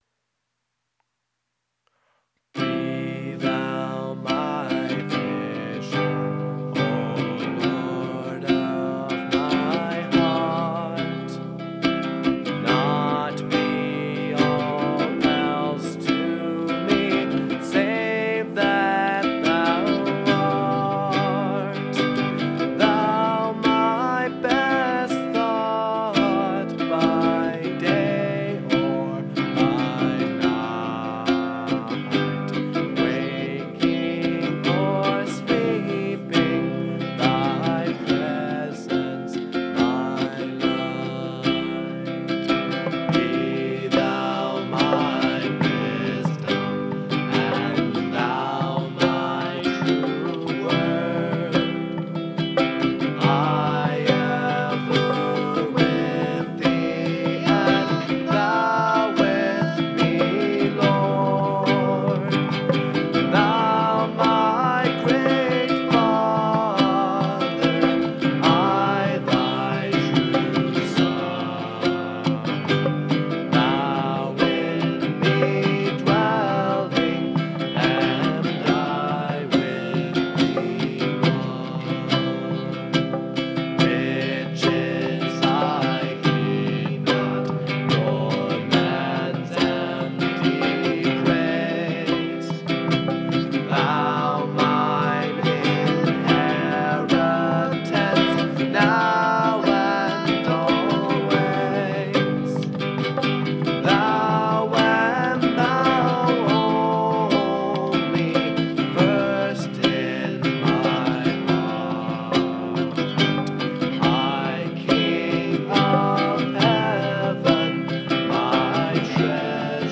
Genre(s): Acoustic
Instrumentation: Djembe, Guitar, Vocals